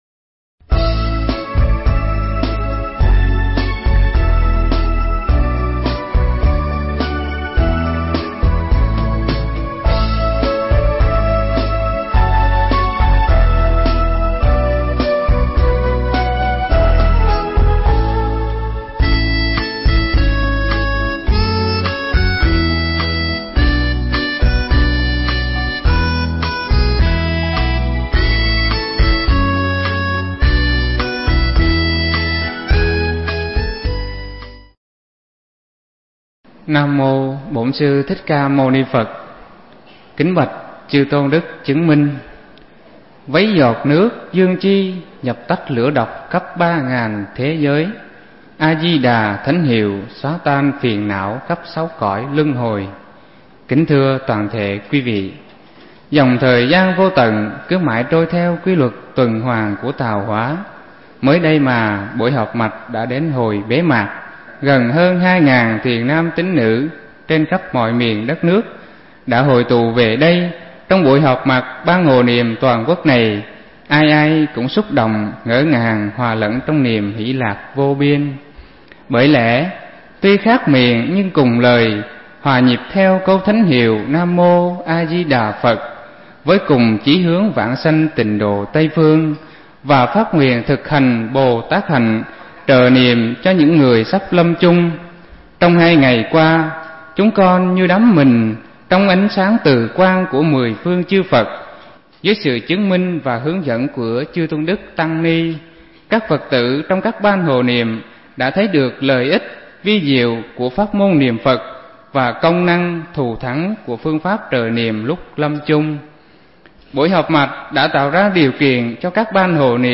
Nghe Mp3 thuyết pháp Bế Mạc Ban Hộ Niệm Toàn Quốc